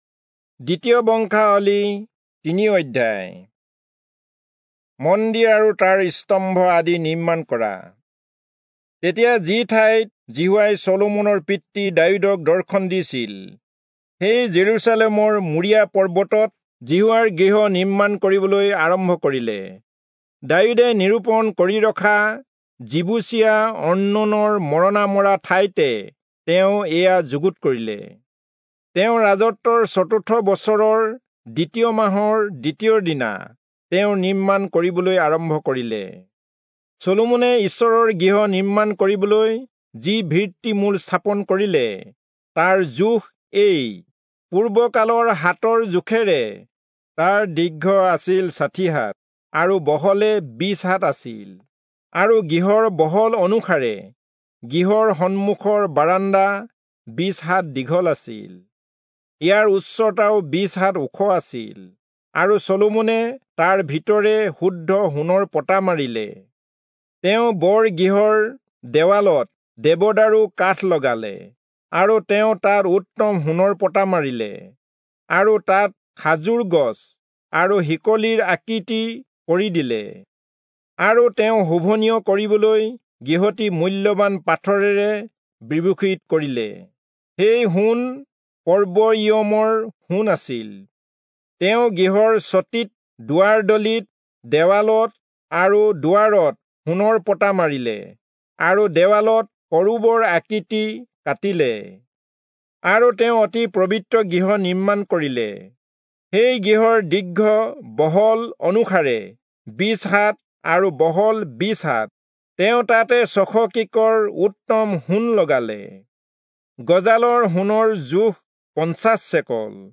Assamese Audio Bible - 2-Chronicles 26 in Irvpa bible version